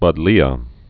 (bŭdlē-ə, bŭd-lēə)